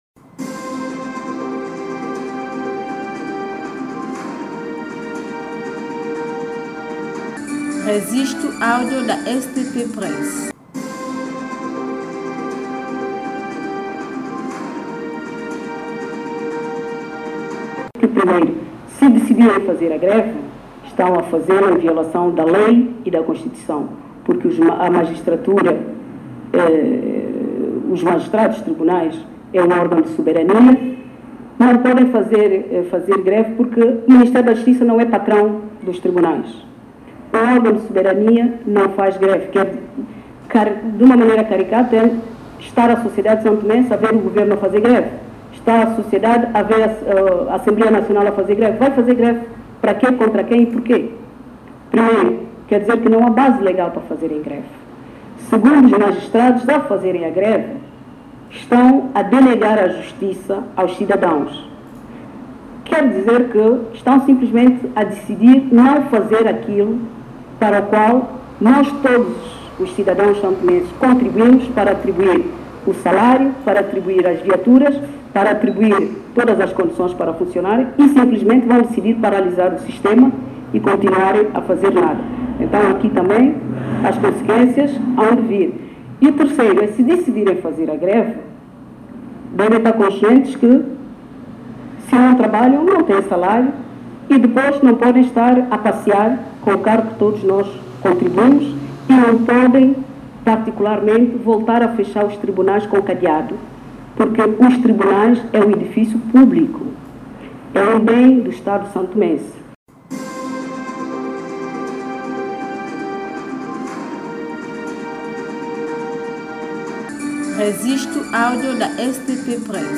Declaração da Ministra da Justiça, Ilza Amado Vaz
Ilza-Amado-Vaz-3.mp3